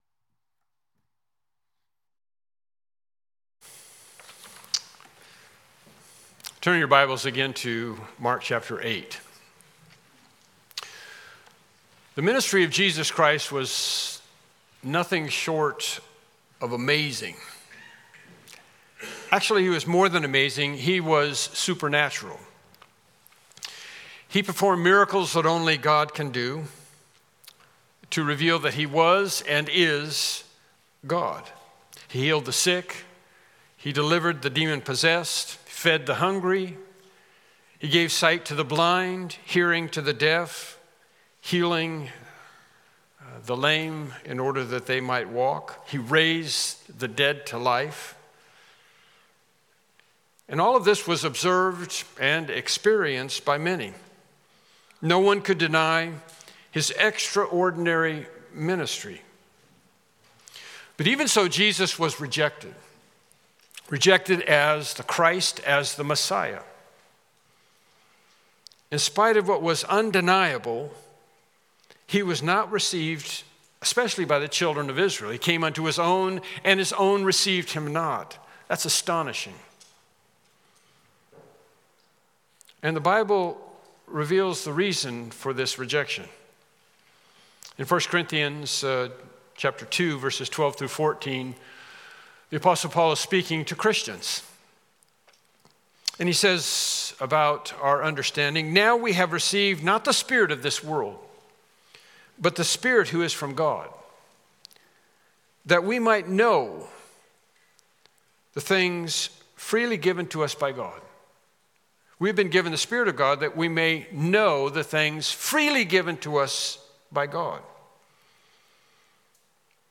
Mark 8:31-33 Service Type: Morning Worship Service « Lesson 8